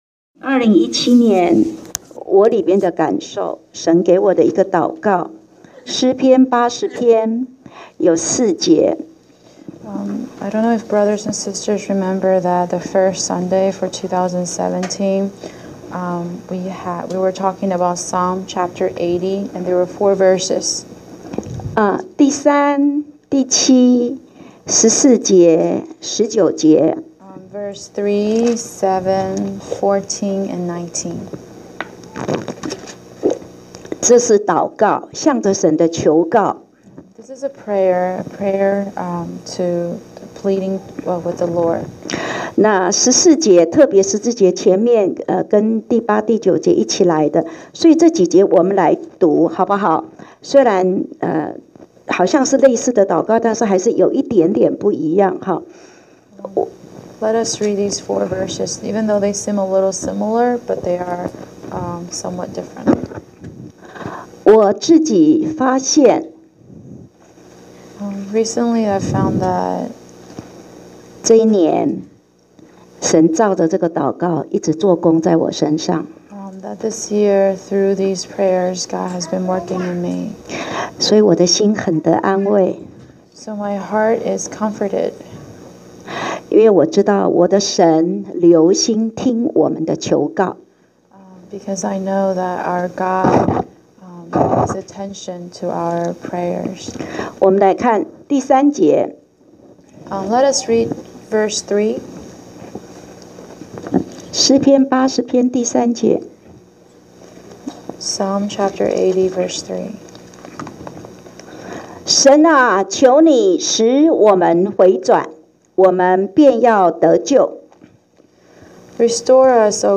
講道下載